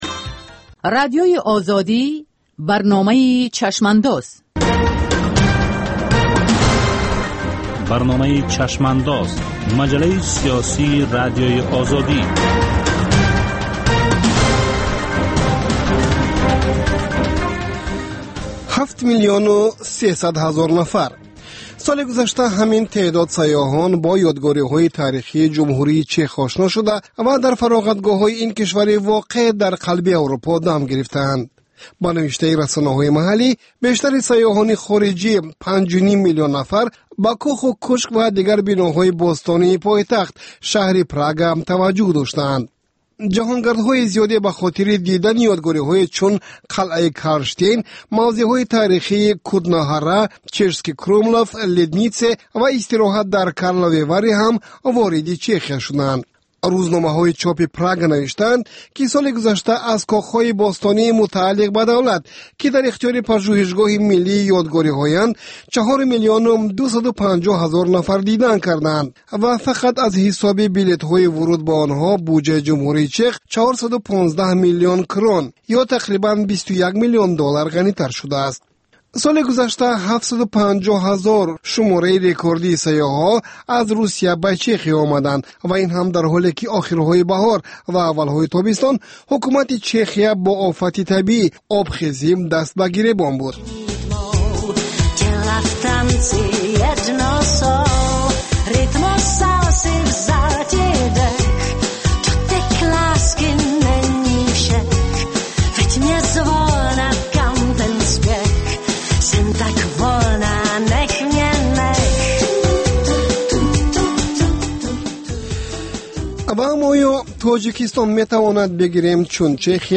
Баррасии рӯйдодҳои сиёсии Тоҷикистон, минтақа ва ҷаҳон дар гуфтугӯ бо таҳлилгарон.